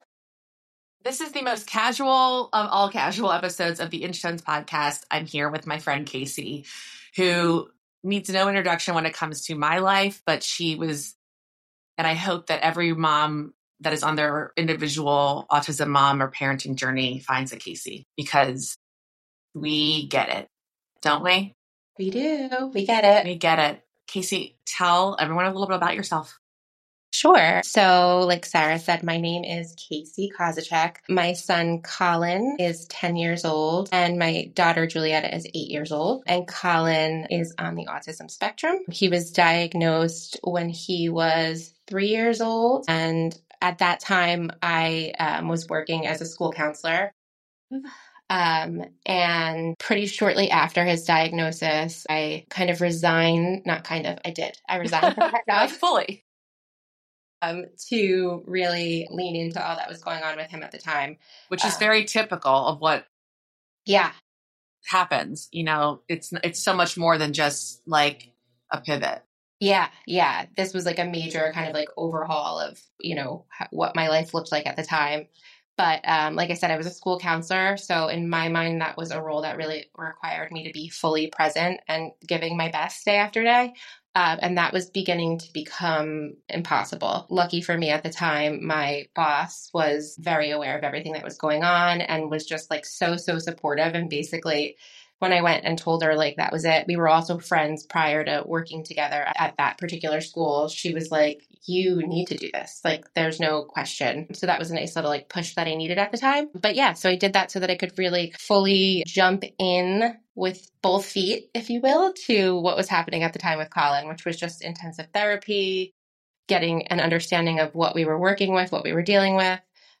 With honesty, warmth, and humor, they explore the deep identity shifts that come with parenting neurodivergent children, and the emotional highs and lows of the journey. The conversation delves into the impact on siblings, the value of online communities and support networks, and how social media can serve as both a lifeline and a storytelling space for autism families.